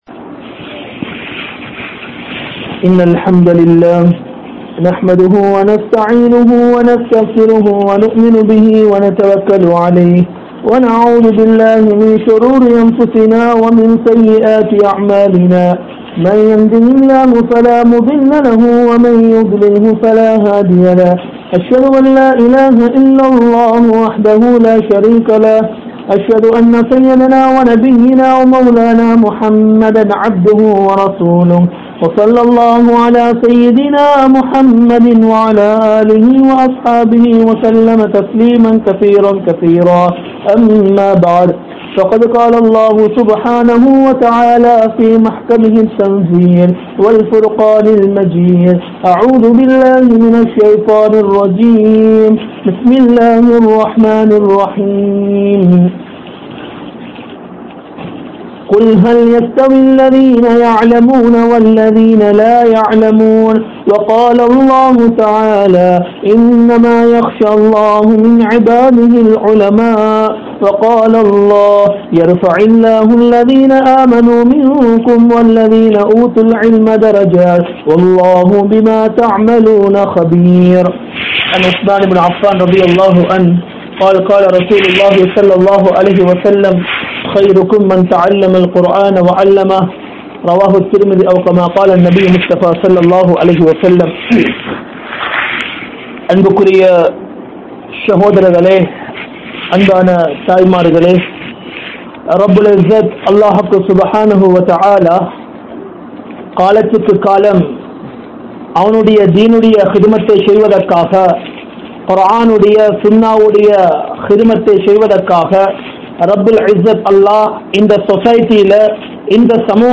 MunMaathiriyaana Islamiya Pengal (முன்மாதிரியான இஸ்லாமிய பெண்கள்) | Audio Bayans | All Ceylon Muslim Youth Community | Addalaichenai